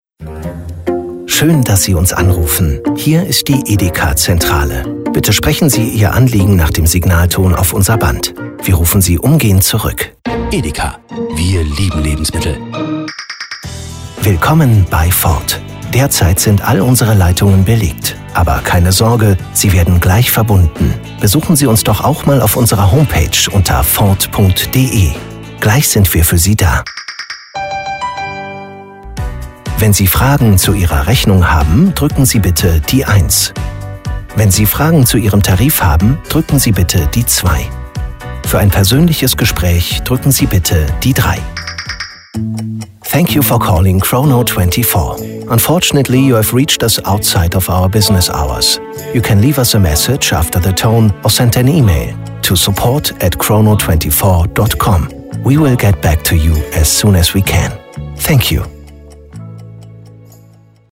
Anrufbeantworter-Ansage Sprecher - Synchronsprecher
🟢 Premium Sprecher